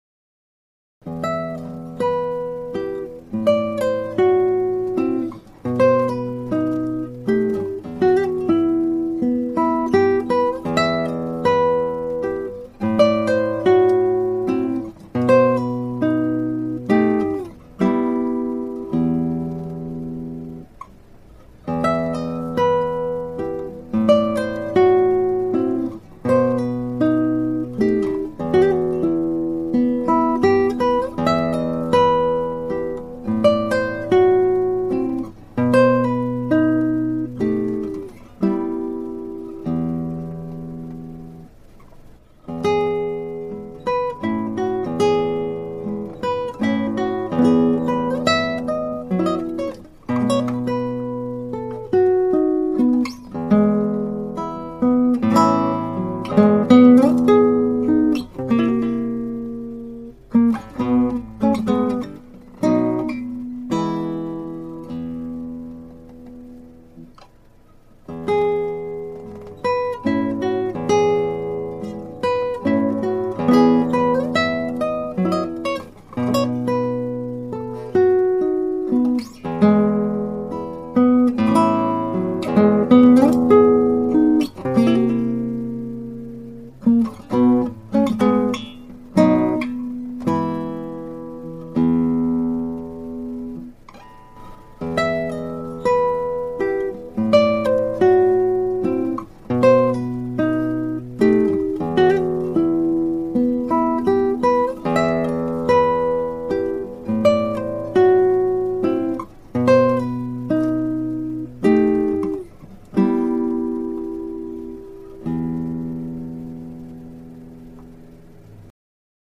(アマチュアのクラシックギター演奏です [Guitar amatuer play] )
練習したのでまた再録しました。
4小節の頭、フレーズの切れ目と解釈しテンポにタメをしました。
その他は楽譜の指示通りのテンポにしメロディー重視で弾きました。
形式は3/4拍子のマズルカで三部形式。